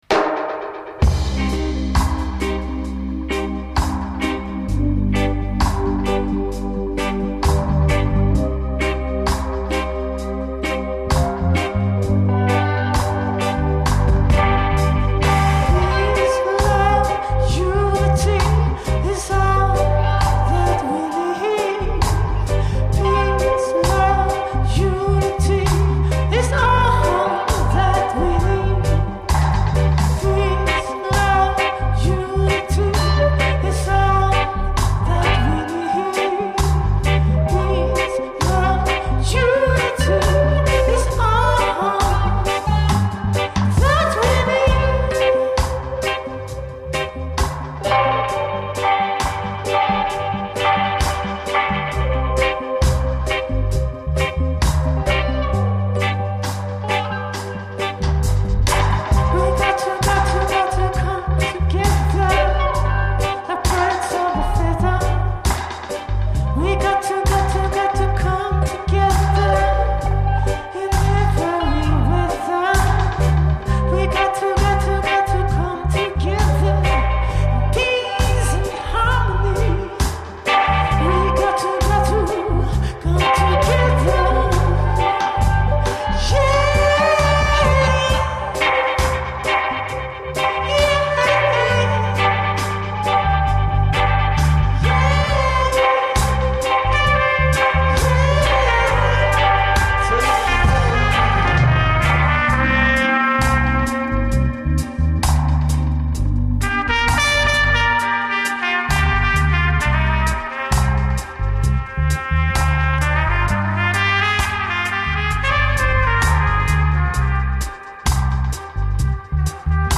Recorded on the south-side of town